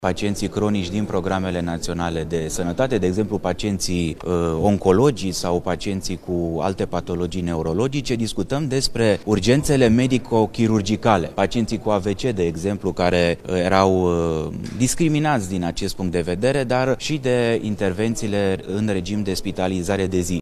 Astăzi, Camera Deputaților a aprobat un proiect de lege prin care prima zi de concediu medical să fie plătită și pentru pacienții cronici și cei care beneficiază de spitalizare. Ministrul Sănătății, Alexandru Rogobete, a explicat care sunt categoriile care vor beneficia din nou de prima zi de concediu medical.